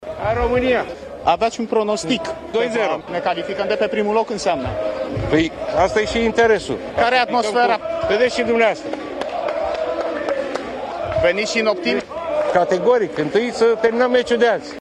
La Frankfurt, el s-a întâlnit cu suporterii naționalei în zona destinată acestora și – într-o scurtă declarație pentru Antena 3, a facut un pronostic legat de partida din această seară.